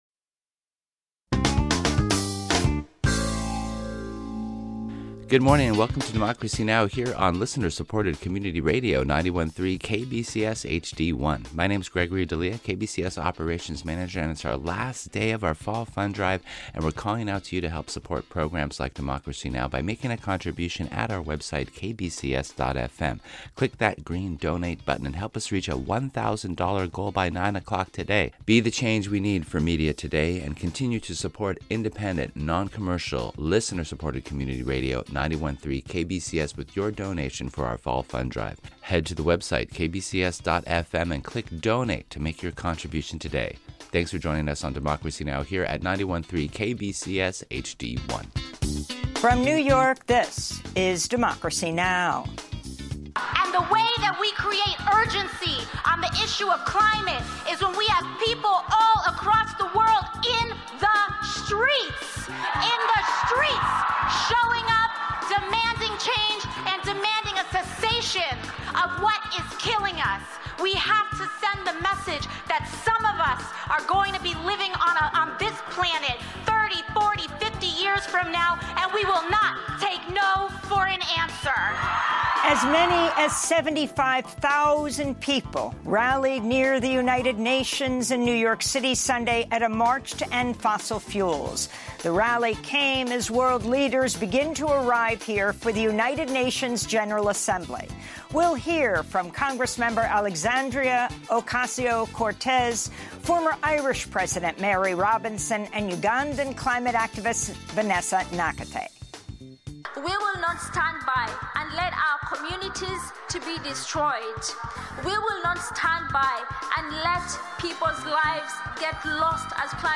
Discover Democracy Now!, your daily global independent news hour with award-winning journalists Amy Goodman and Juan González. Get breaking news headlines, in-depth interviews, and diverse perspectives on the world's most pressing issues."